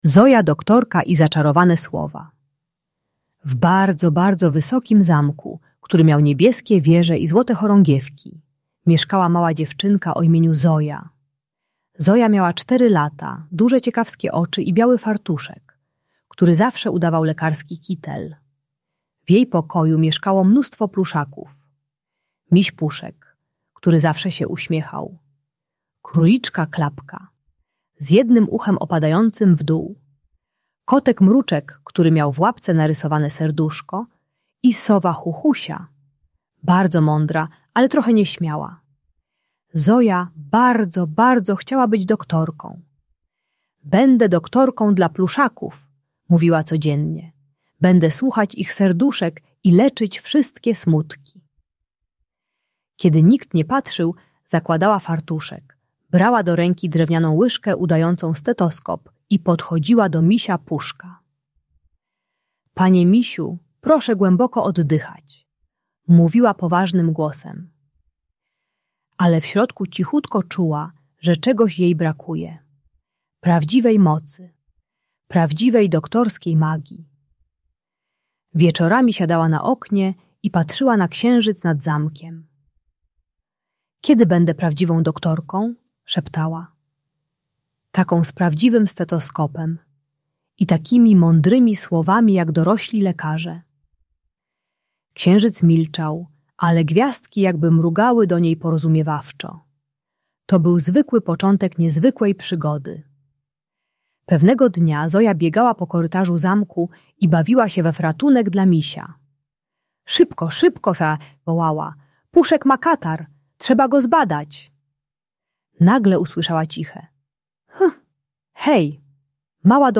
Zoja Doktorka i Zaczarowane Słowa - Szkoła | Audiobajka
Uczy techniki "3 prób" - krótkich codziennych ćwiczeń bez presji, oraz jak mówić o frustracji: "Teraz się złoszczę". Audiobajka wspierająca terapię logopedyczną i radzenie sobie z frustracją podczas nauki wymowy.